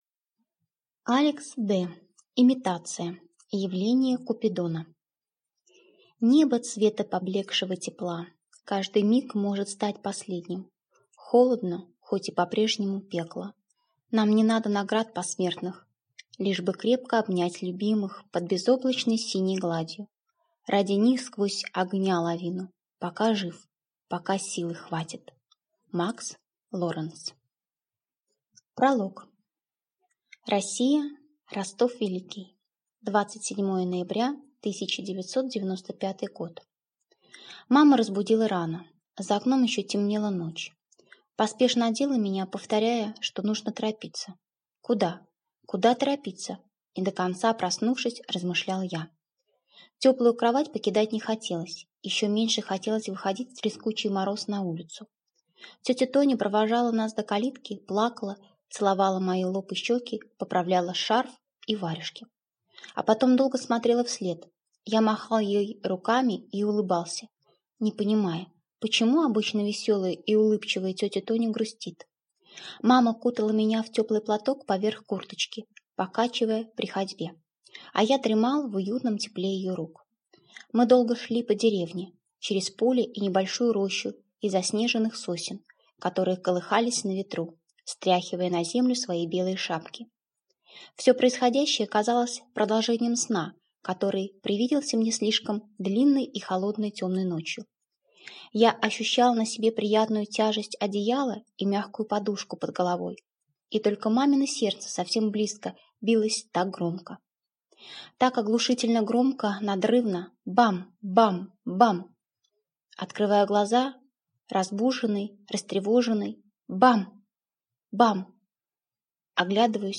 Аудиокнига Имитация. Явление «Купидона» - купить, скачать и слушать онлайн | КнигоПоиск